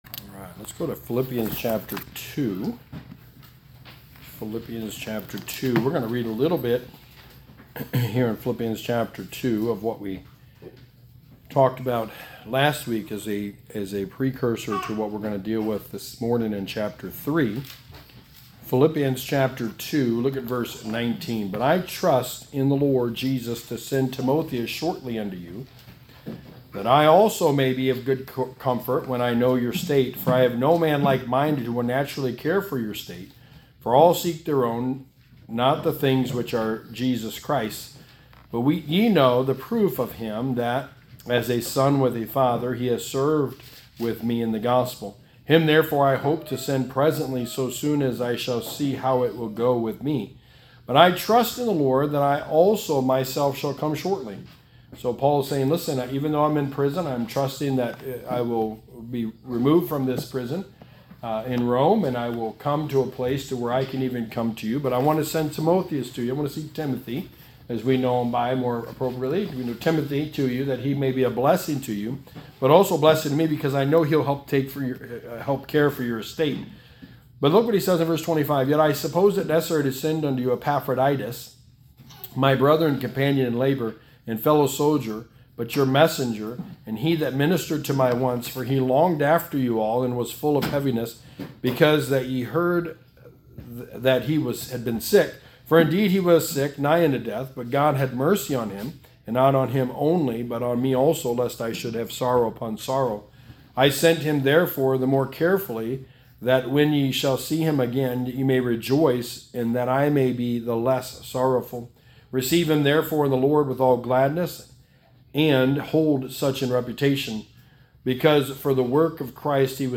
Passage: Phillipians 3:1-2 Service Type: Sunday Morning Sermon